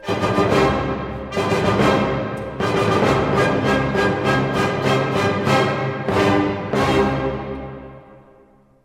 listen to the conclusion of the first movement of Ludwig van Beethoven's 's Symphony #1, which uses a string of perfect fourths (combined with minor seconds) to emphasize the tonic pitch.